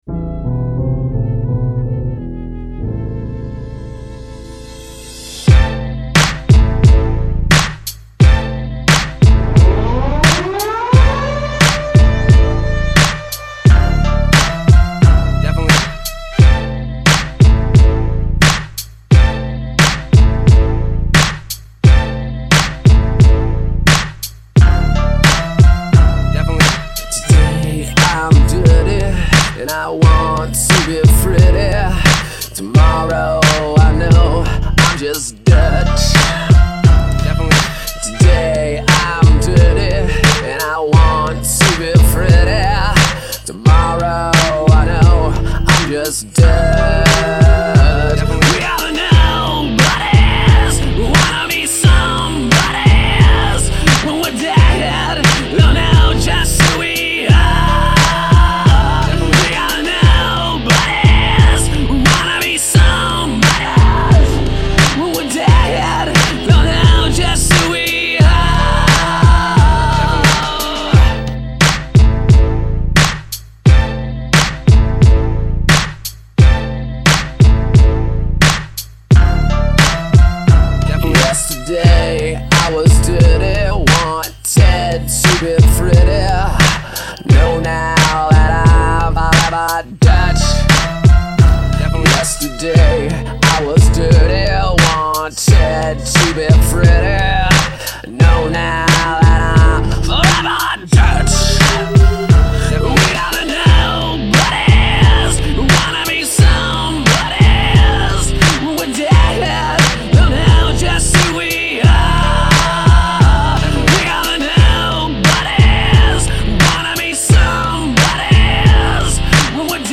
all mashups